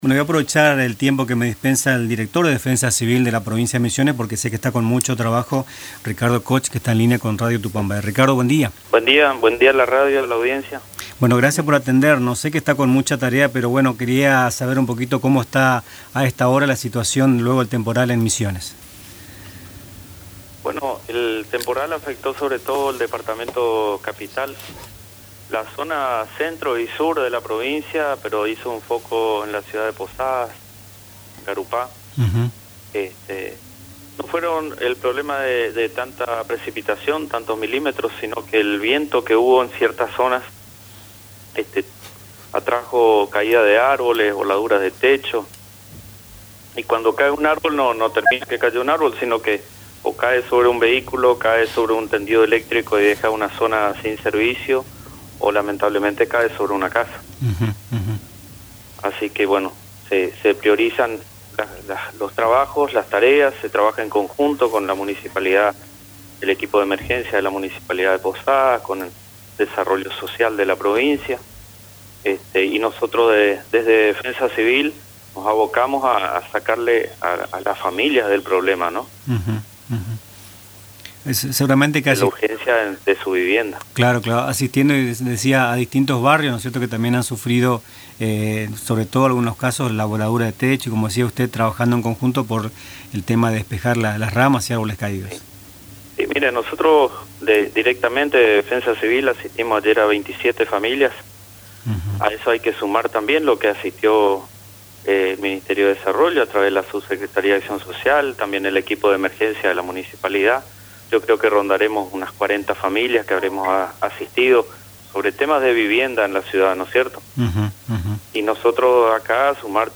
En “Nuestras Mañanas”, entrevistamos al director de Defensa Civil, Ricardo Koch quién indicó que, hoy tendrán más datos sobre las familias afectadas por temporal de ayer. Sin embargo, resaltó que gracias a las tareas de saneamiento en Posadas no se produjeron inundaciones en los barrios, sobre todo en arroyos que históricamente desbordaban al caer 15 o 20 mm de agua.